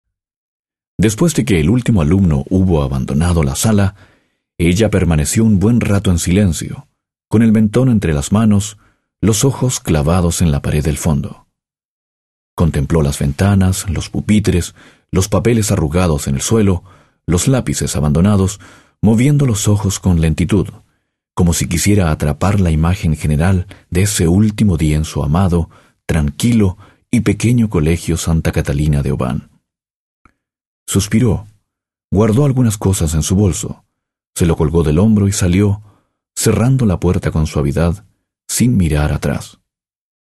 Sprecher, Spanisch südamerikanisch, chilenisch.
spanisch Südamerika
Sprechprobe: eLearning (Muttersprache):